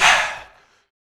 Vox (Metro Breath).wav